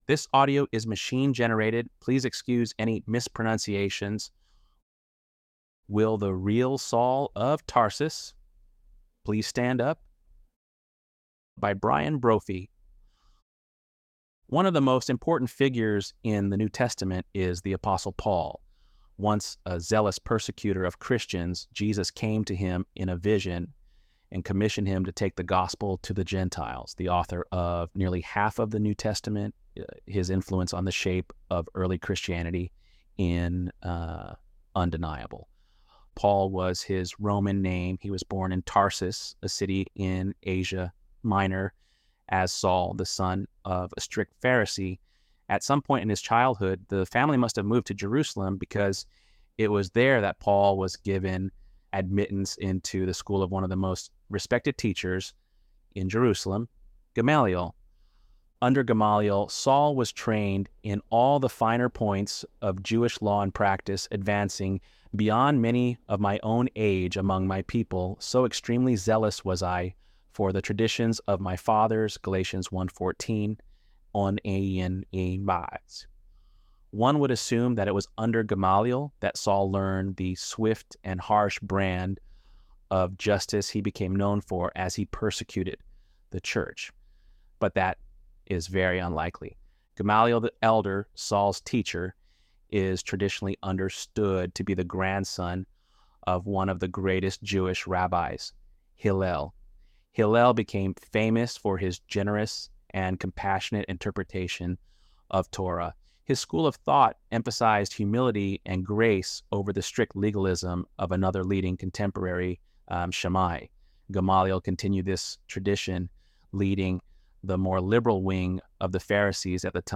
ElevenLabs_10.24-1.mp3